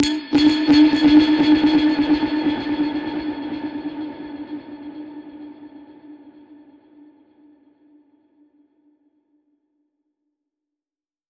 Index of /musicradar/dub-percussion-samples/85bpm
DPFX_PercHit_D_85-04.wav